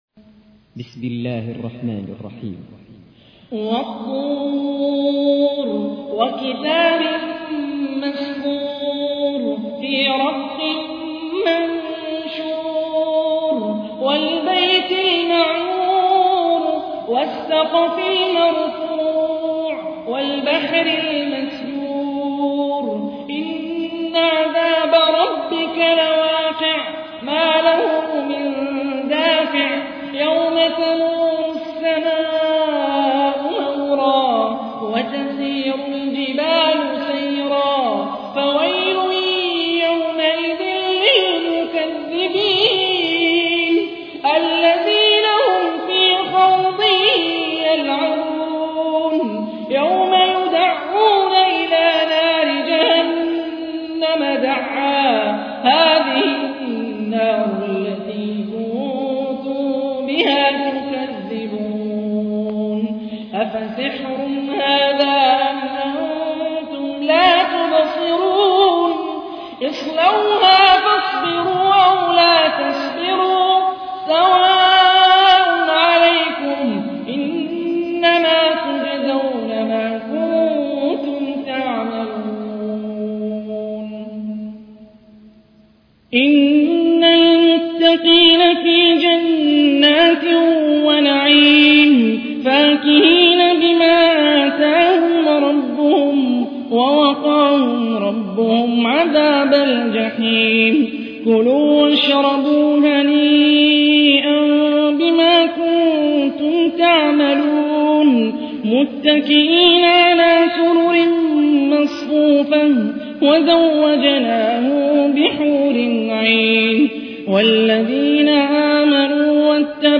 تحميل : 52. سورة الطور / القارئ هاني الرفاعي / القرآن الكريم / موقع يا حسين